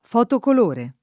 vai all'elenco alfabetico delle voci ingrandisci il carattere 100% rimpicciolisci il carattere stampa invia tramite posta elettronica codividi su Facebook fotocolore [ f q tokol 1 re ] (meno bene fotocolor [ f q tok 0 lor ]) s. m. (tecn.)